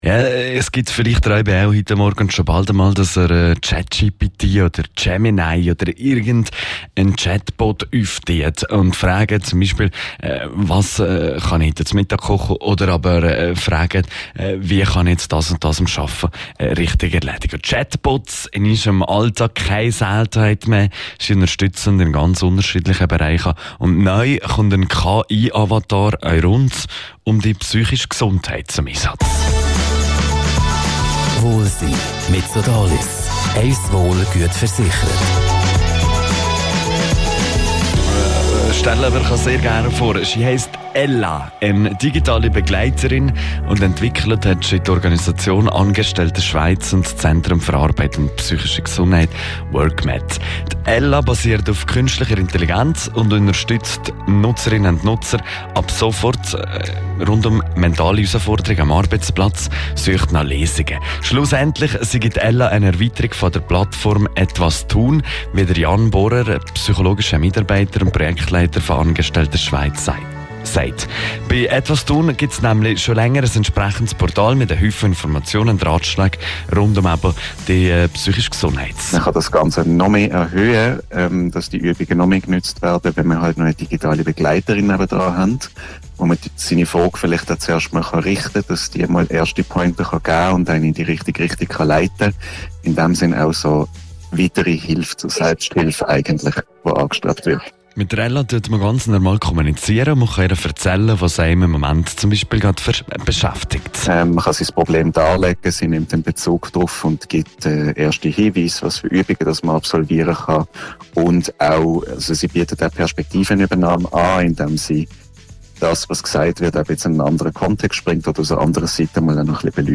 Radio Rottu Oberwallis berichtet über Ella